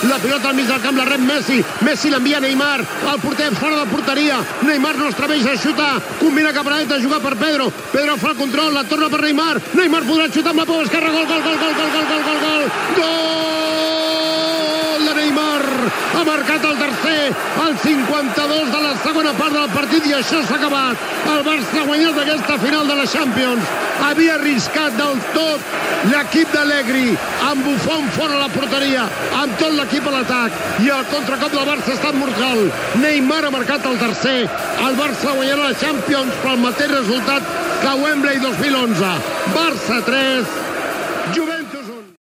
Transmissió de la final de la Copa d'Europa de futbol masculí, des de l'Olympiastadion de Berlín, entre el Futbol Club Barcelona i la Juventus de Milàs.
Narració del gol de Neymar (3-1)
Esportiu